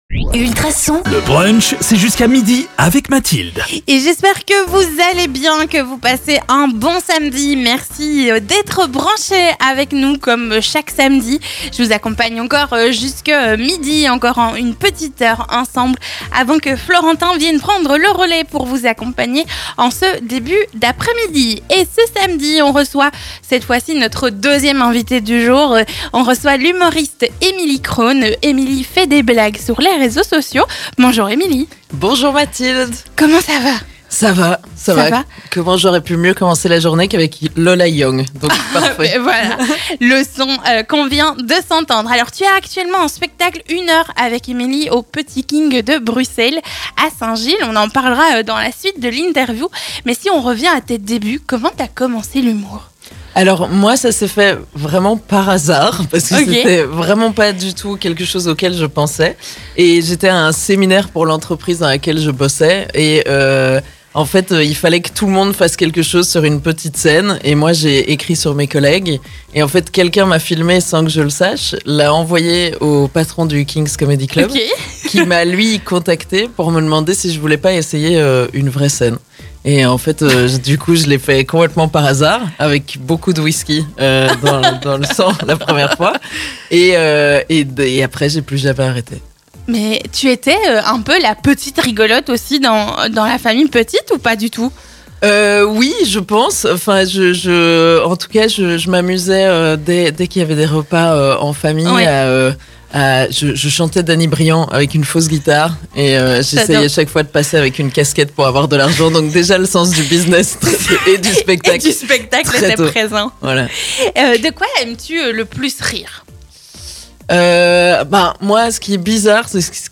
L'invité.e du Brunch